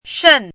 “胂”读音
shèn
胂字注音：ㄕㄣˋ
国际音标：ʂən˥˧
shèn.mp3